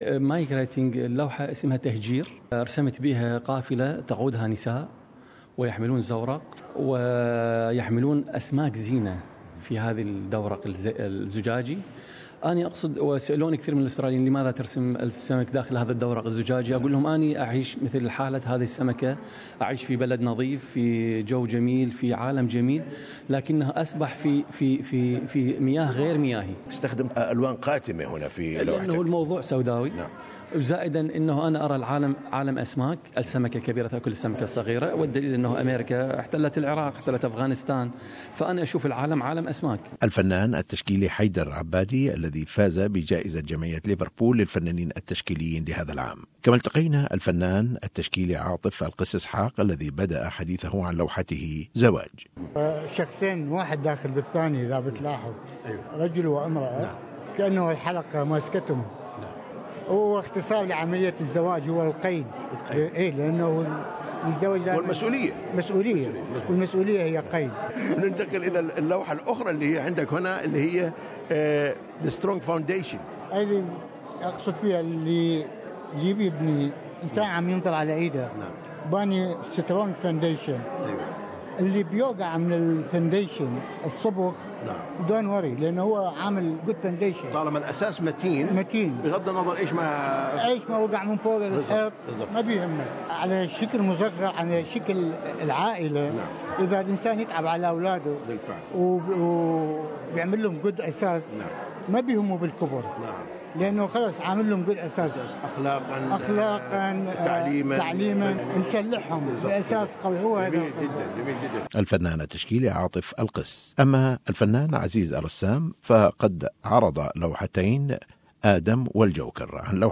speaks to artists at Exhibition launch